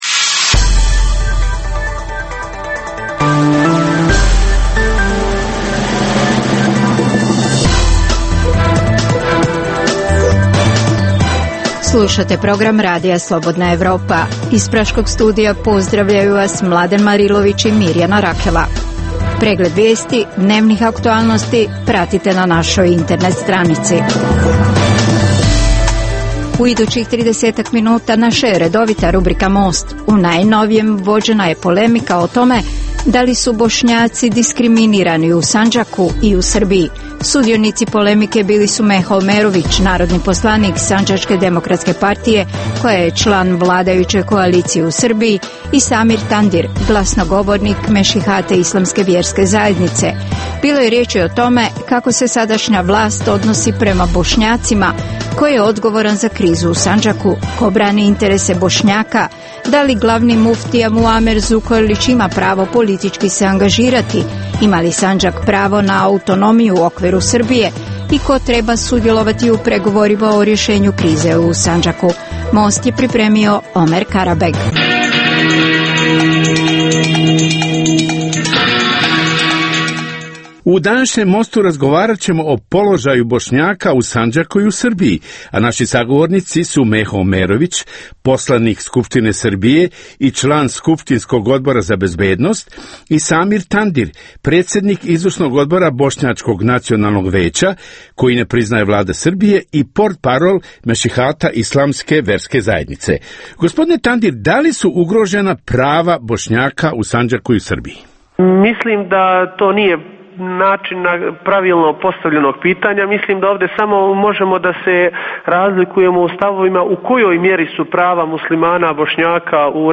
U regionalnom programu nedjelja je rezervirana za emisiju Pred licem pravde i Most. U ovom Mostu vođena je polemika o tome da li su Bošnjaci diskriminarini u Sandžaku i u Srbiji.